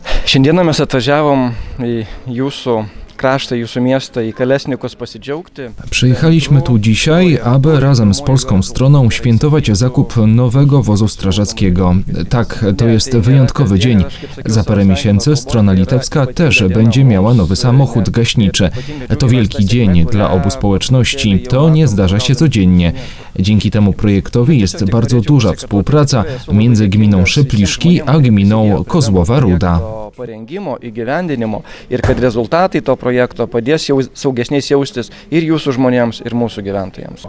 – Już niedługo podobny wóz pojawi się tez u nas. To wielki dzień dla obu społeczności. – mówi Mariusz Zitkus, zastępca mera Kozłowej Rudy.